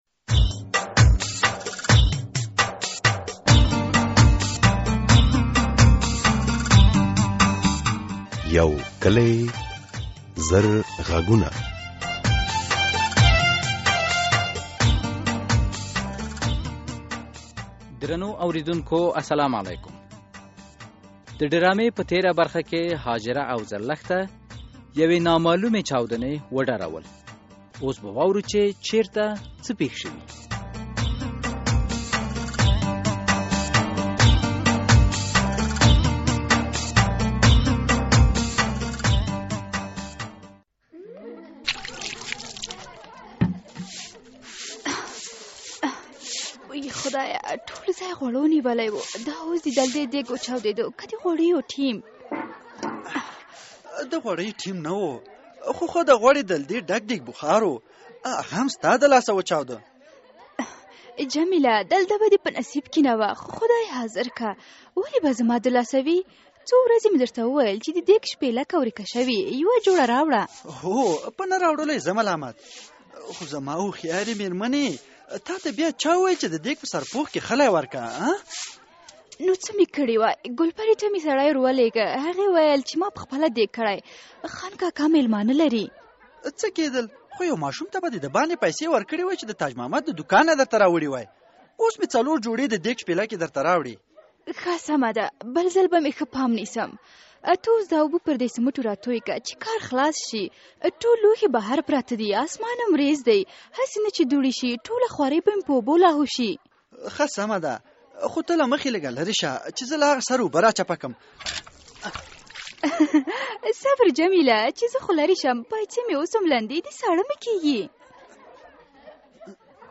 یو کلي او زر غږونه ډرامه هره اوونۍ د دوشنبې په ورځ څلور نیمې بجې له ازادي راډیو خپریږي.